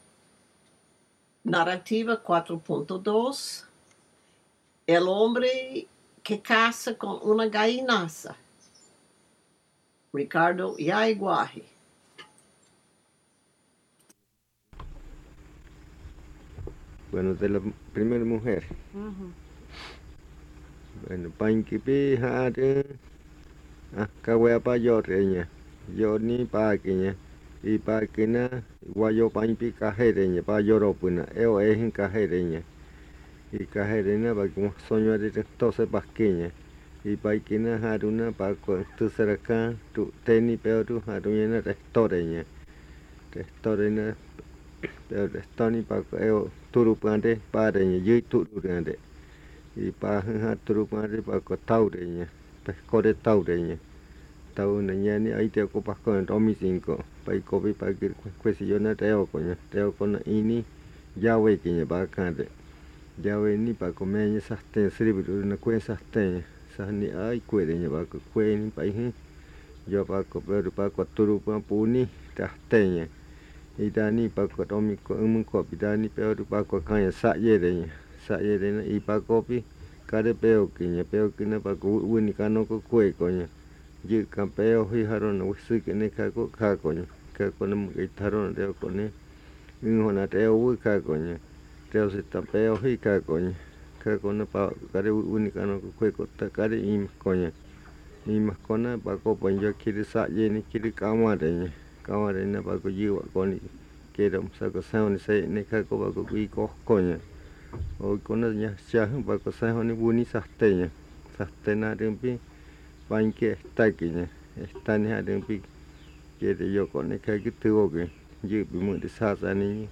Buenavista, río Putumayo (Colombia)